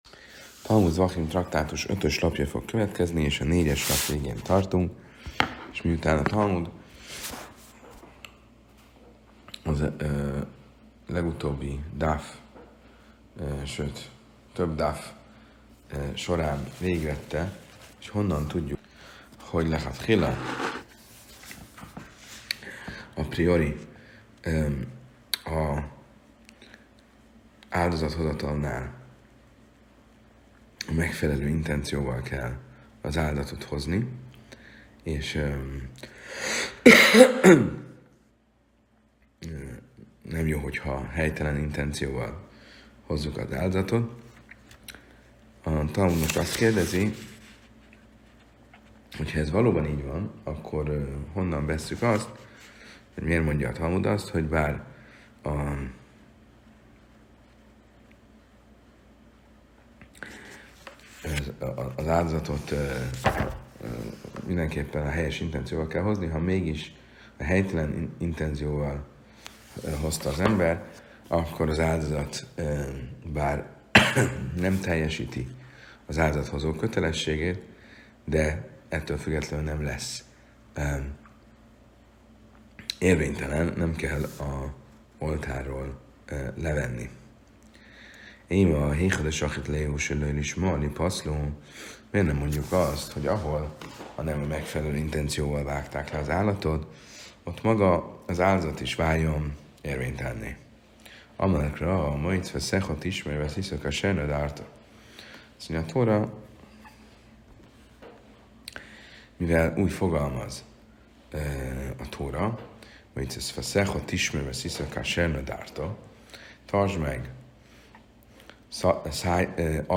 Az előadás során a rabbinikus logika precíz láncolata vezet végig bennünket a törvények finom különbségein.